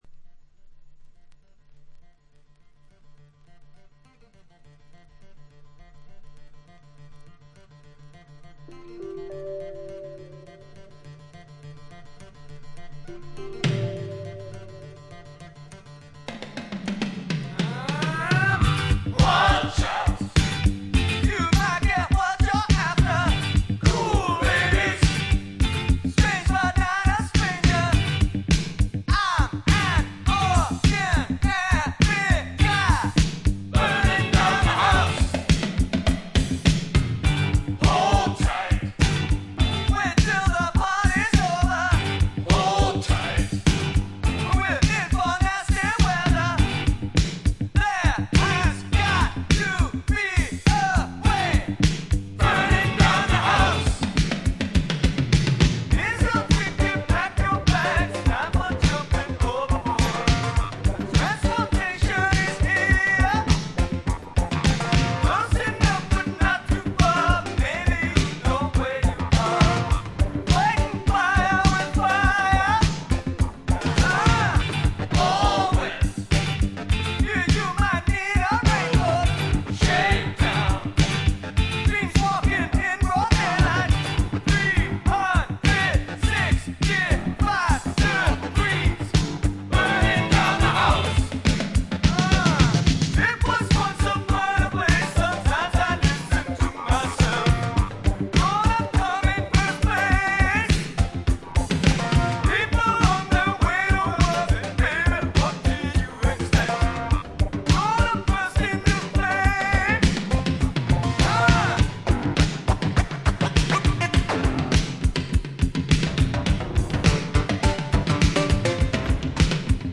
ホーム > レコード：米国 ロック